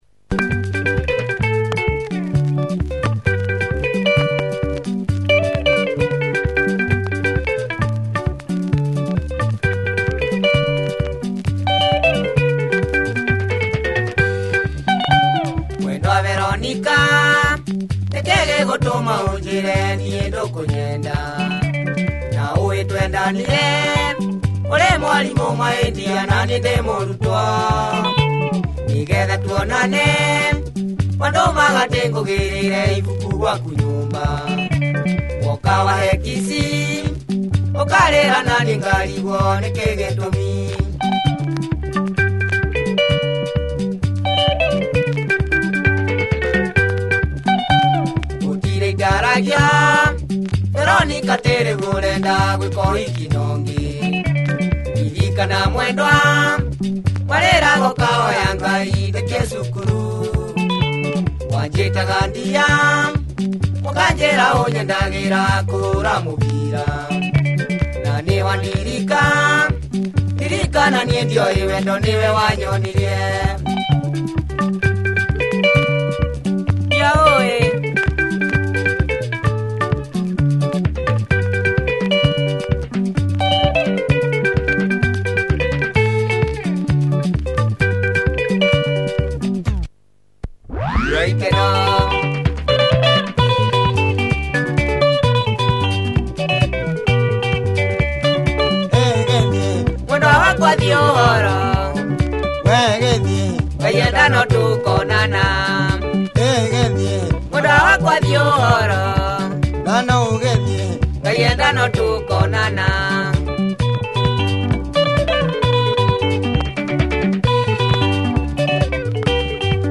benga